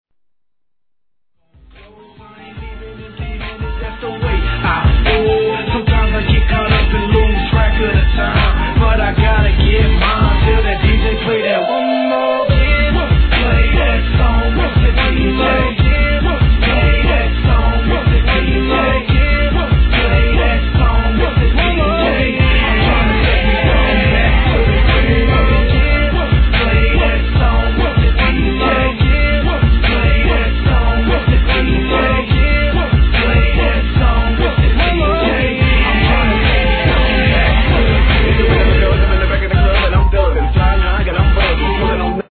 HIP HOP/R&B
(BPM145)